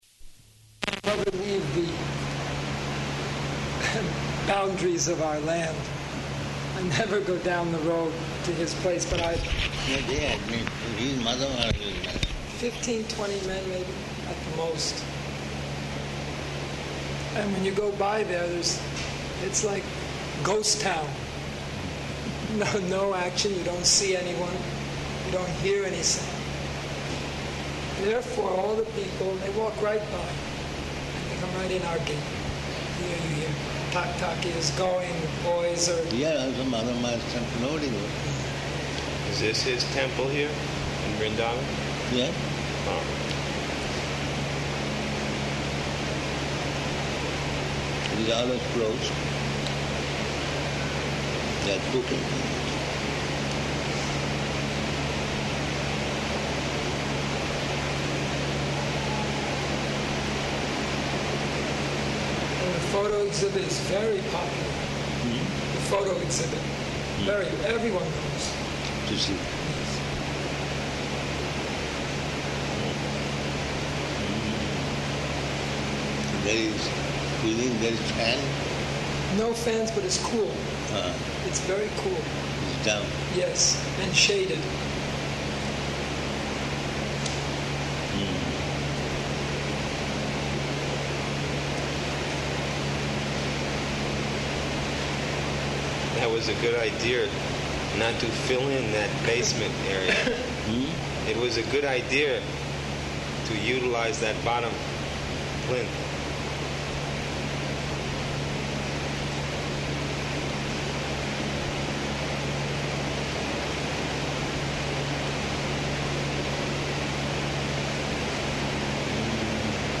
Type: Conversation
Location: Vṛndāvana